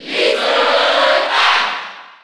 Category:Crowd cheers (SSB4) You cannot overwrite this file.
Little_Mac_Cheer_French_PAL_SSB4.ogg